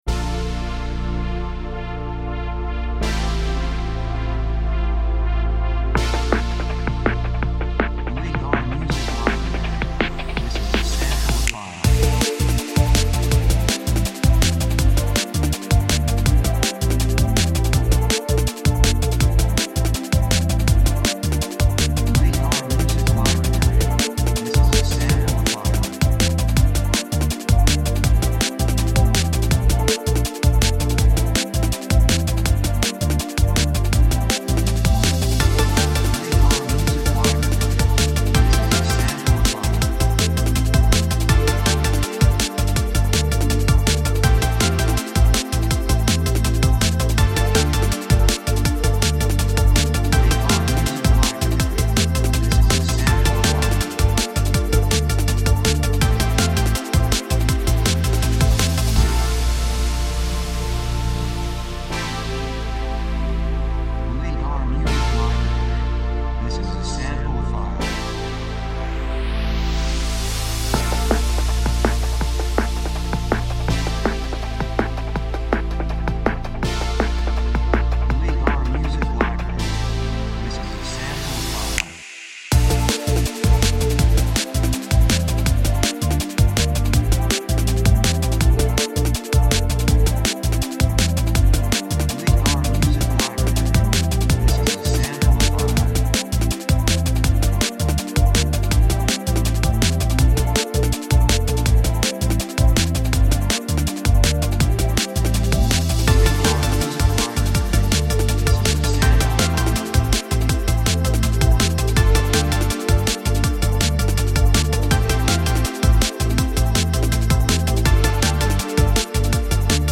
2:32 163 プロモ, エレクトロニック